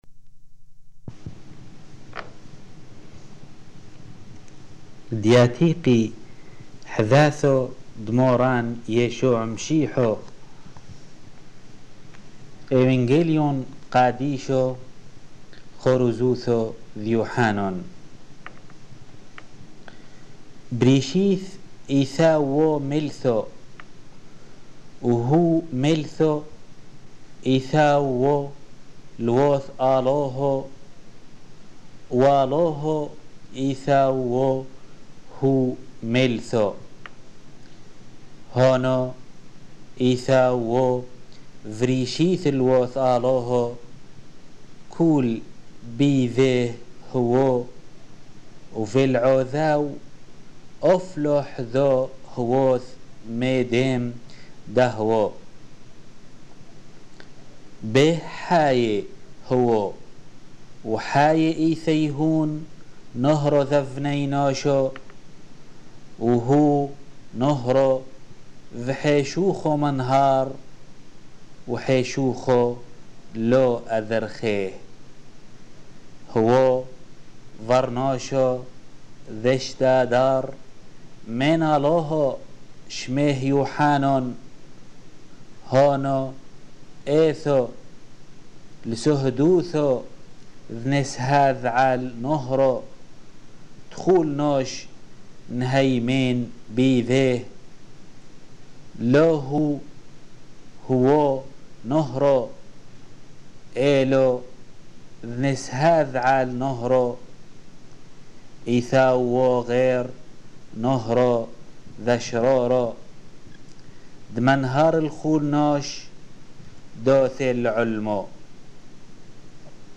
Lecture de l'évangile de Jean, chap. 1-4 en langue syriaque (peshitta)